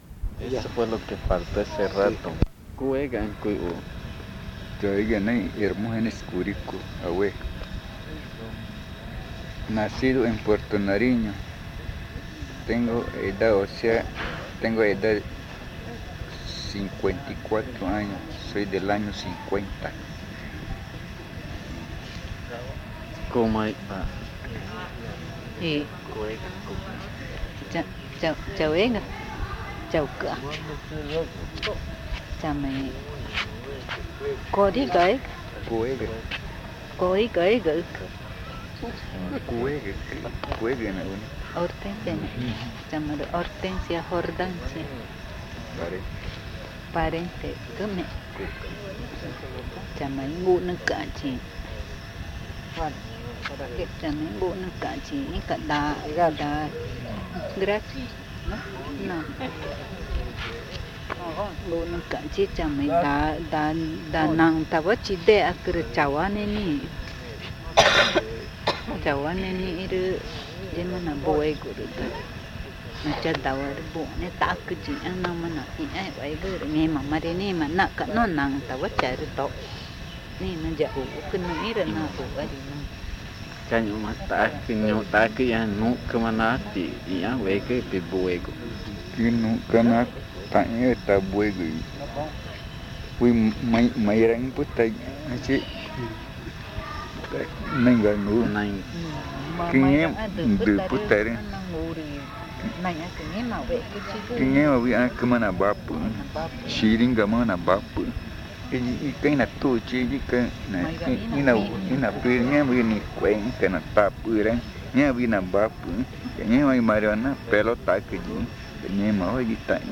San Antonio de los Lagos, Amazonas (Colombia)